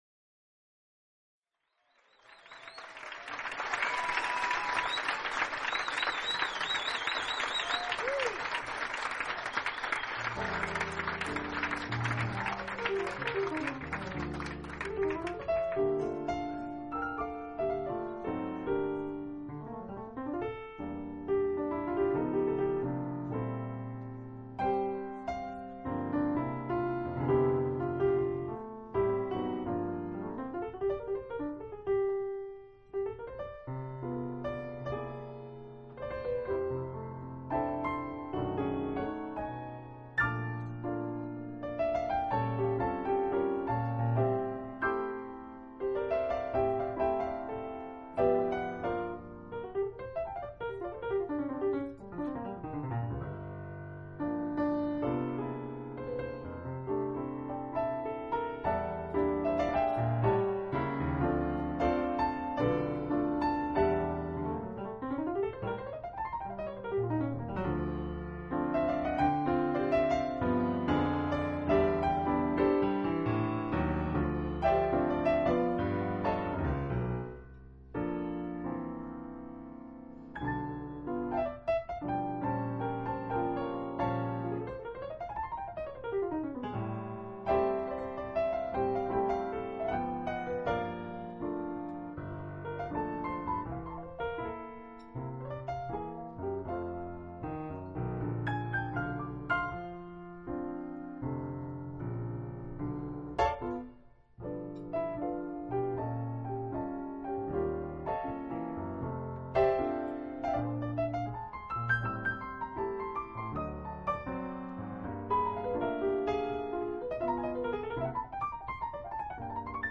音樂類別 ： 爵士樂 ． 爵士三重奏
專輯特色 ： 藍調爵士俱樂部現場錄音